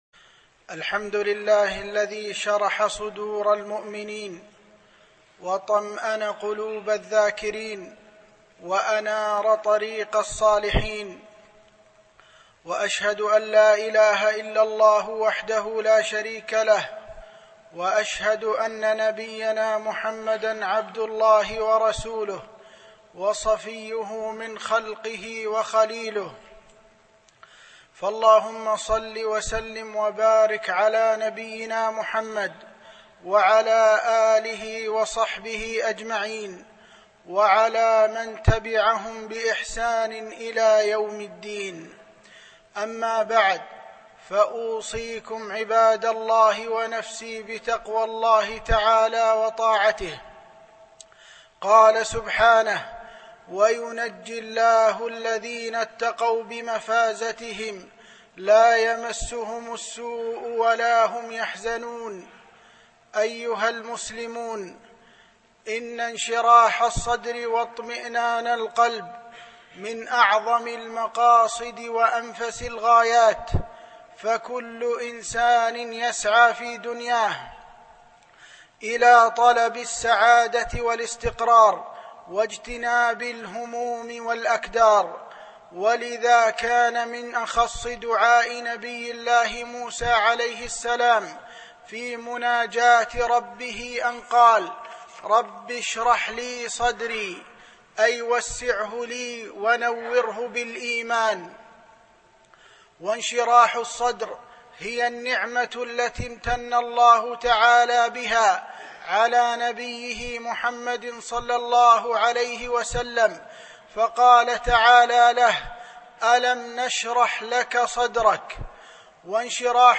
خطب منبرية